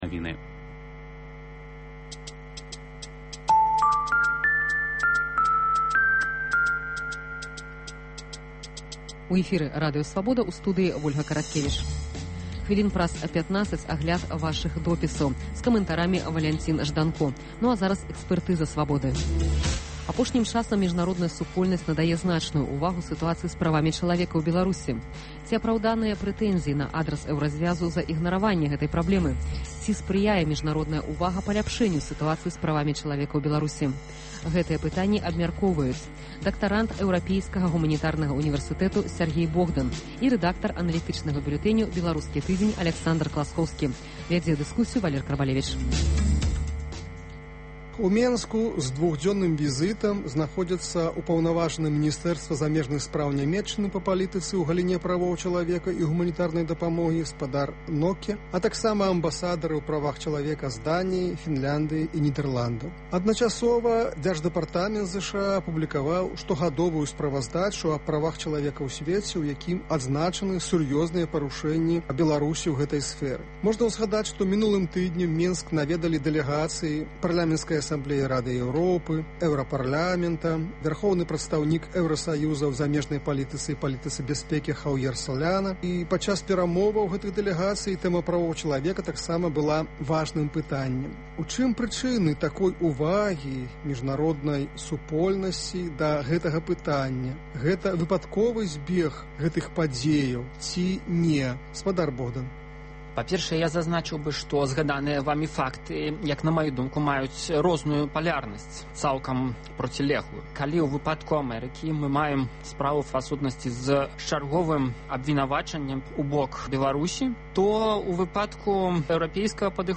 Ці апраўданыя папрокі на адрас Эўразьвязу за ігнараваньне гэтай праблемы? Ці садзейнічае міжнародная ўвага паляпшэньню сытуацыі з правамі чалавека ў Беларусі? Гэтыя пытаньні абмяркоўваюць за круглым сталом